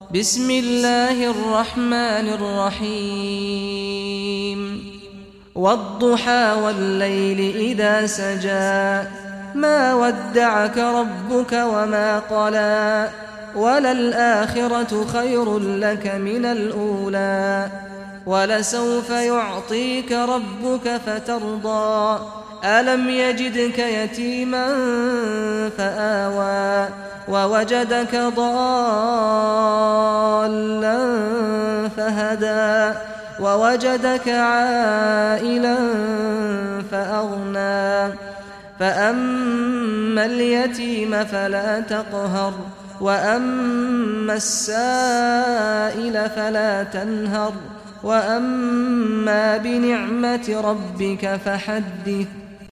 Surah Ad-Duhaa Recitation by Sheikh Saad al Ghamdi
Surah Ad-Duhaa, listen or play online mp3 tilawat / recitation in Arabic in the beautiful voice of Sheikh Saad al Ghamdi.